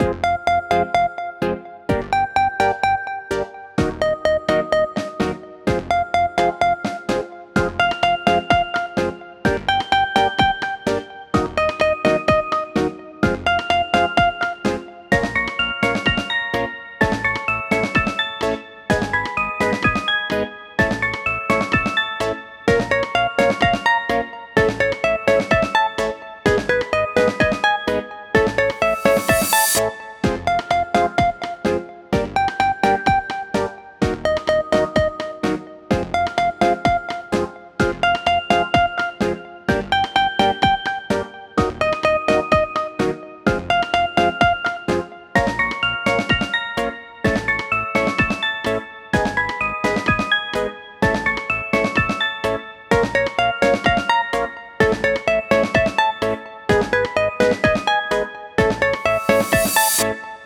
BPM：127 キー：A# ジャンル：あかるい、おしゃれ 楽器：シンセサイザー